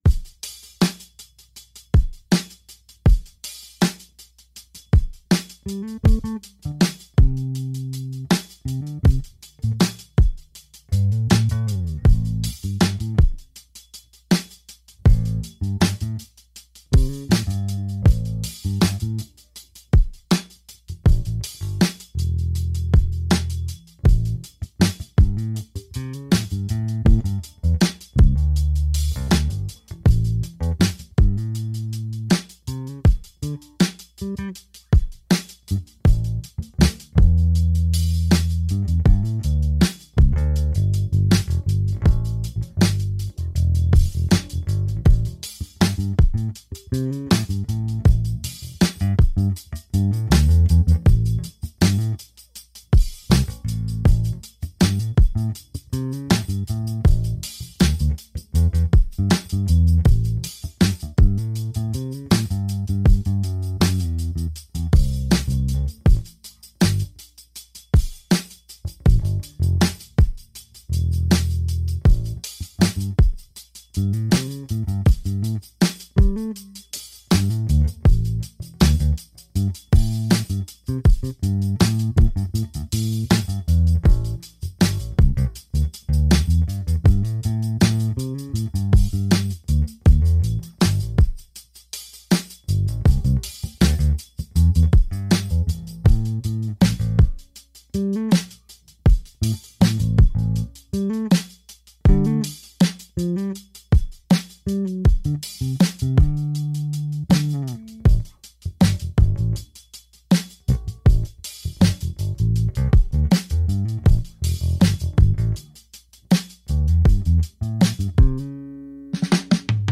PB Impro Ampeg SVX a v něm B15R bez drive, tohle je s mírně zataženou clonou a dobarvené v mixu.